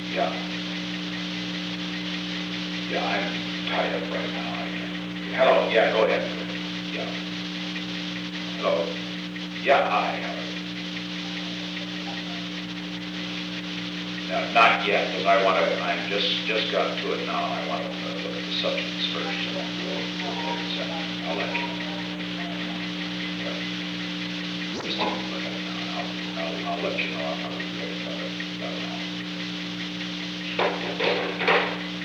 On January 24, 1972, President Richard M. Nixon and William L. Safire met in the President's office in the Old Executive Office Building from 3:08 pm to 3:09 pm. The Old Executive Office Building taping system captured this recording, which is known as Conversation 317-008 of the White House Tapes. Nixon Library Finding Aid: Conversation No. 317-8 Date: January 24, 1972 Time: 3:08 pm - 3:09 pm Location: Executive Office Building William L. Safire talked with the President.